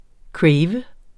Udtale [ ˈkɹεjvə ]